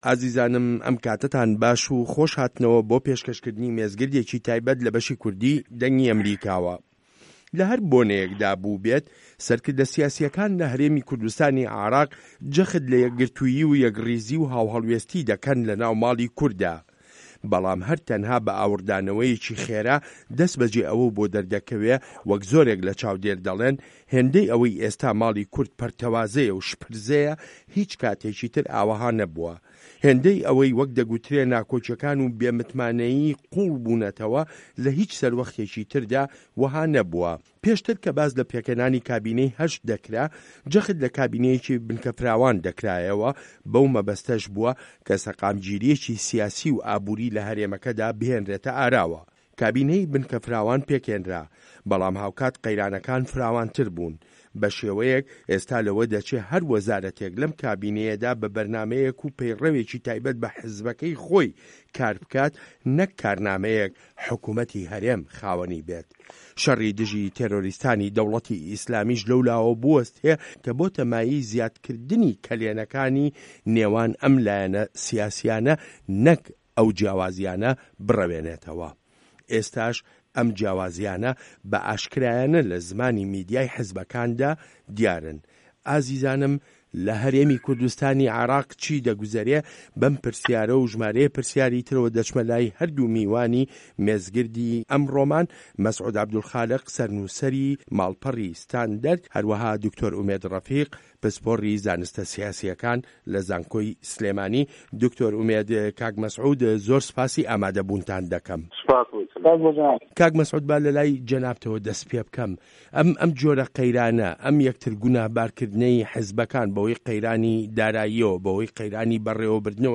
مێزگرد: هه‌رێمی کوردستان و قه‌یرانی دارایی و به‌ڕێوه‌بردن